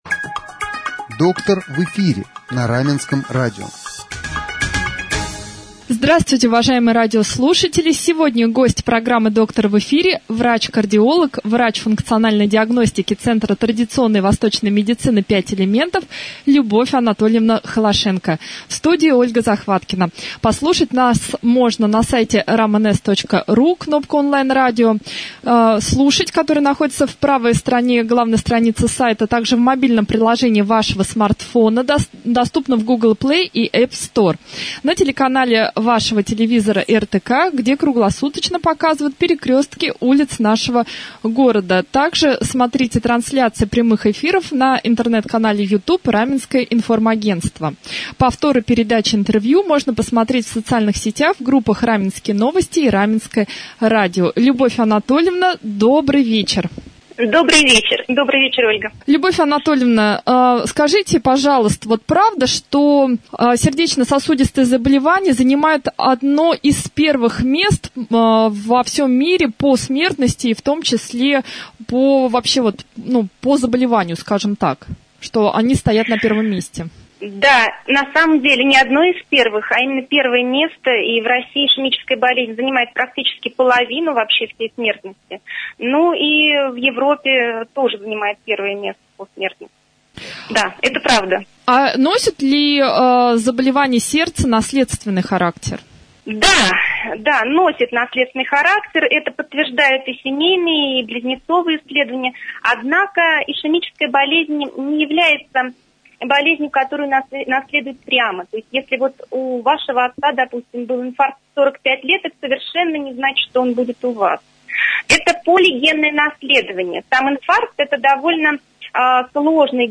стала гостем программы «Доктор в эфире» на Раменском радио